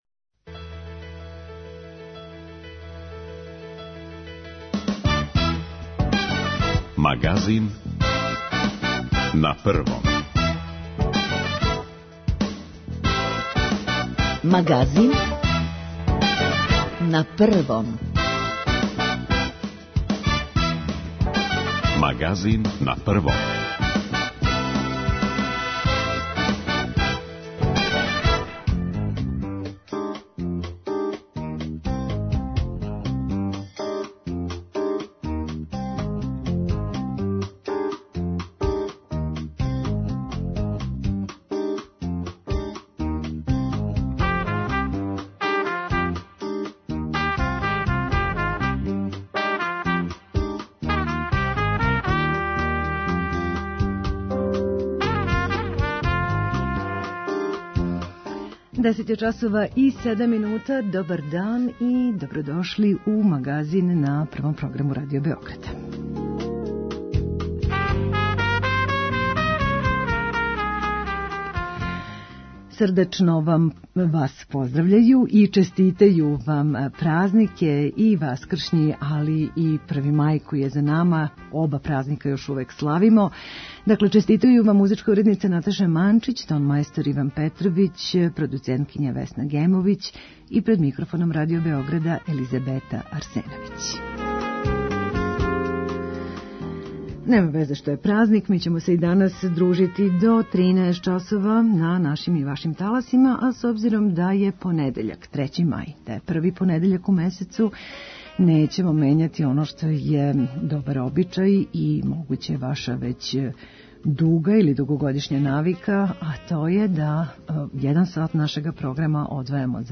У, надамо се, опуштенијој атмосфери, иако нам вирус и даље, да кажемо, дише за вратом, позваћемо вас да нам се јавите и да и овог првог понедељкиа у месецу кажете своје мишљење о нашем програму.